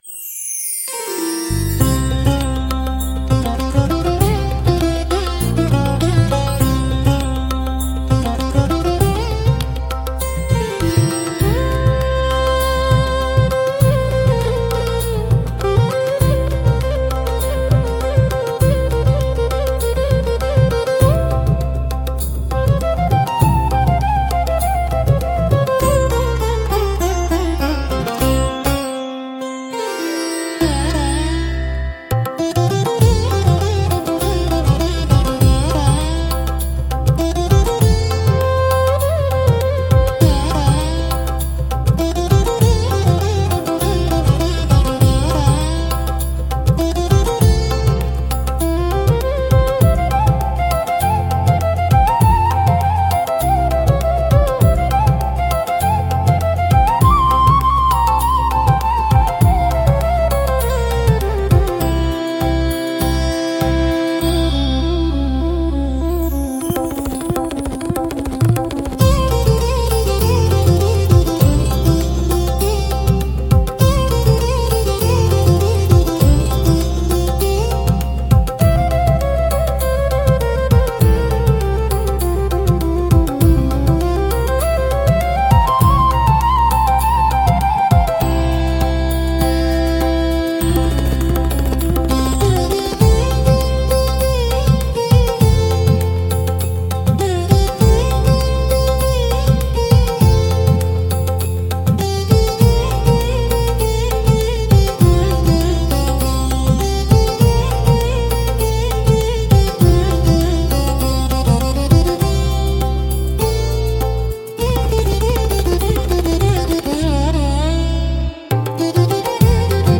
インドは、シタールを主体にした独特の旋律と豊かな装飾音が特徴で、瞑想的かつ神秘的な雰囲気を持ちます。
長く伸びるフレーズと複雑なリズムが伝統的なインド古典音楽の情緒を深く表現し、心を落ち着かせる効果があります。